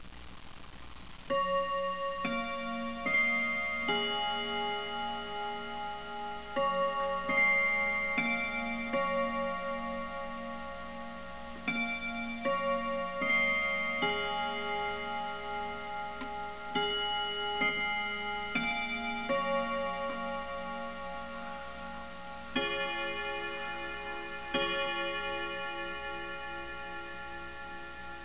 "Foreign" German Westminster Clock
This is my nicest sounding westminster clock.
Westminster_4-4.wav